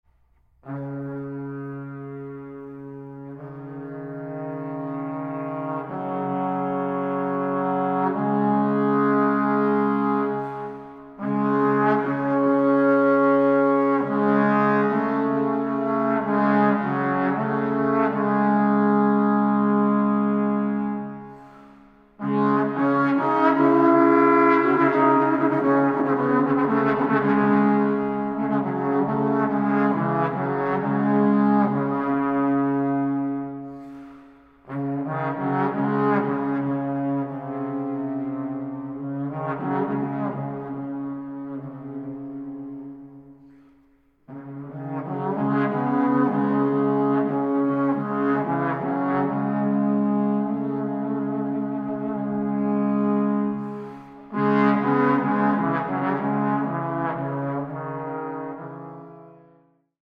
Historical Drums
Recording: Gut Hohen Luckow, 2024